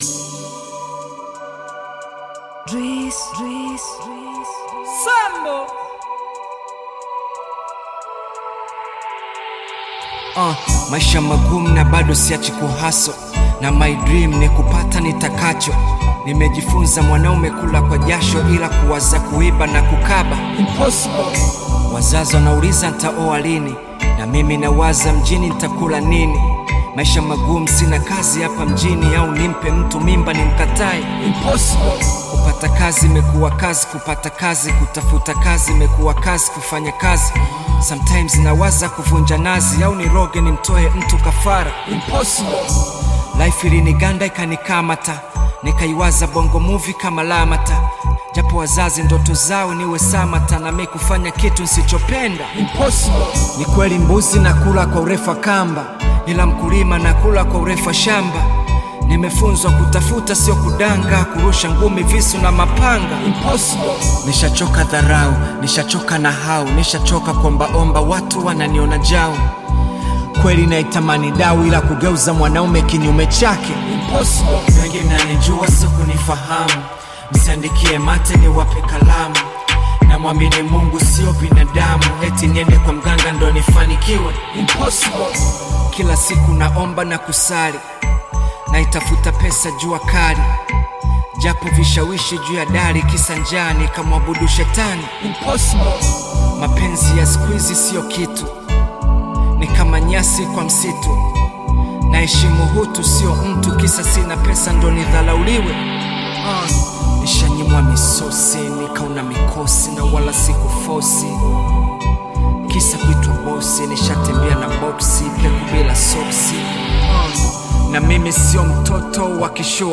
Bongo Flava music track
Tanzanian Bongo Flava artist, singer, and songwriter